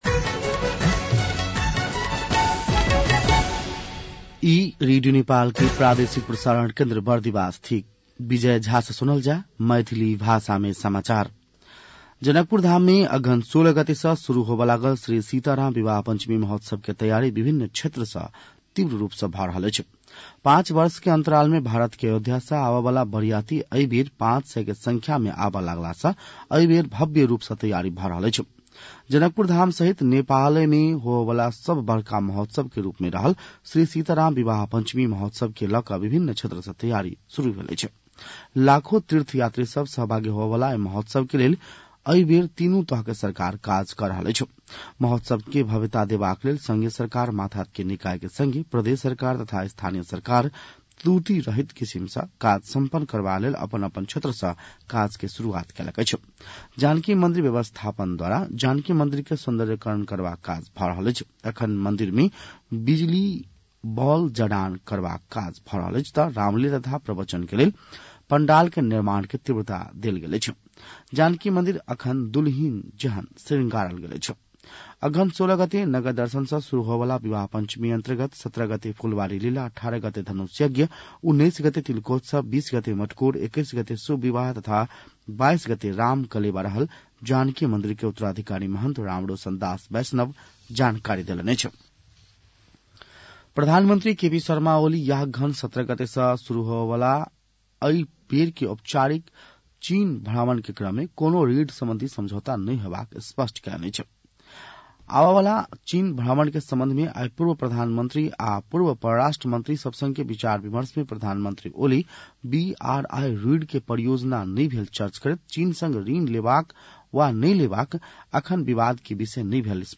मैथिली भाषामा समाचार : ११ मंसिर , २०८१